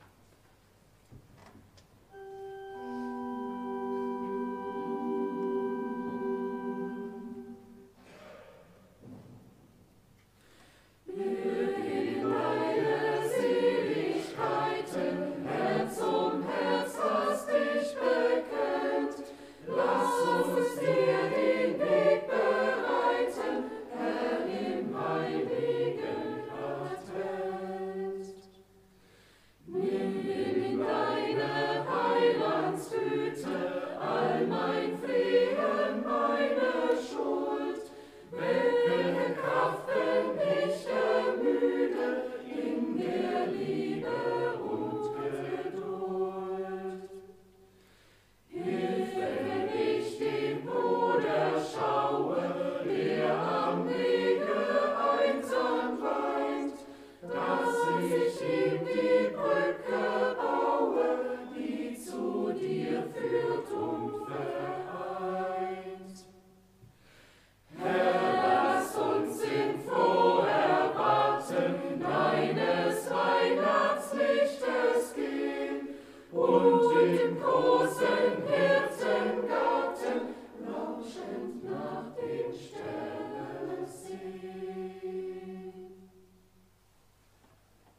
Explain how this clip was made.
Audiomitschnitt unseres Gottesdienstes vom 1.Avent 2024